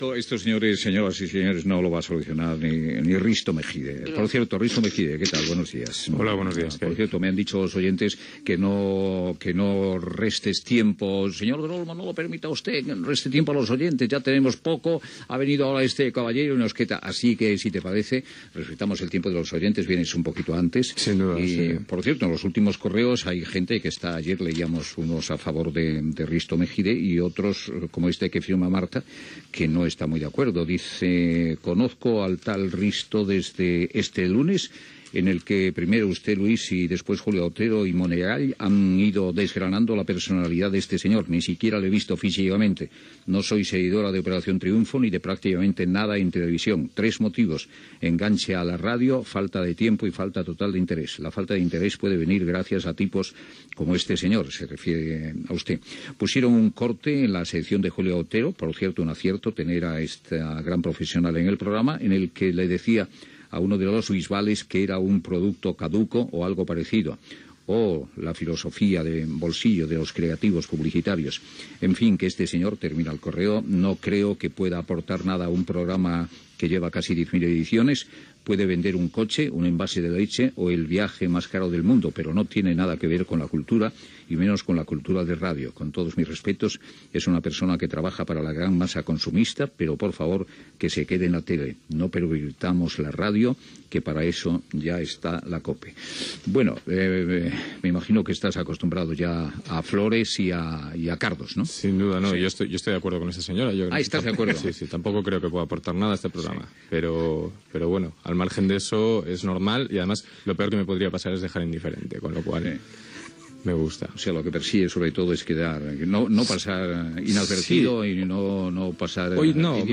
Conversa amb el col·laborador Risto Mejide i comentaris de l'audiència.
Info-entreteniment